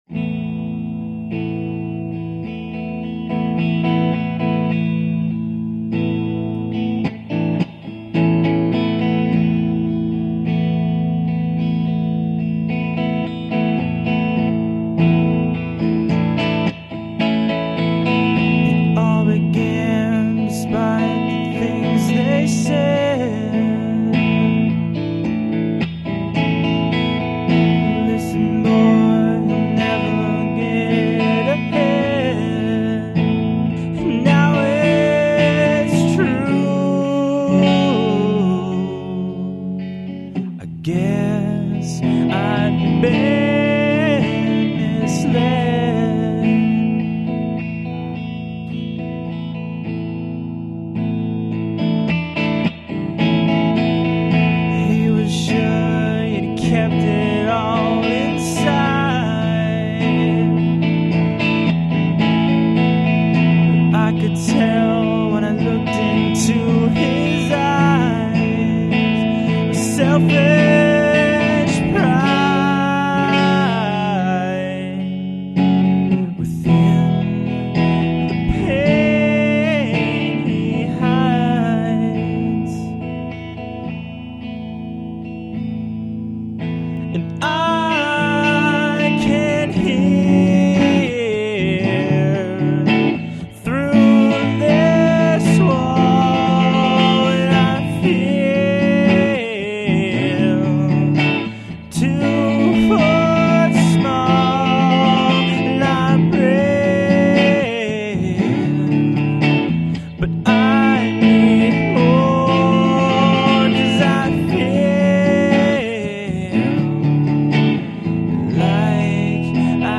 these three musicians